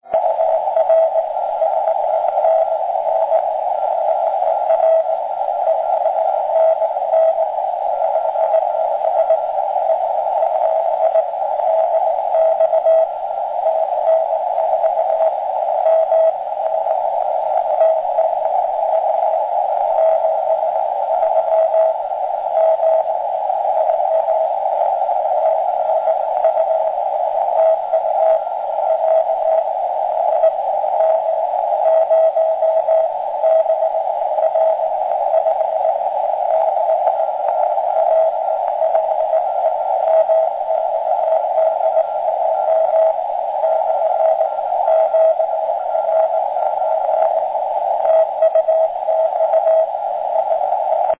Now with extreme signals. Recording below was on the low TX dipole just to show how good the propagation was: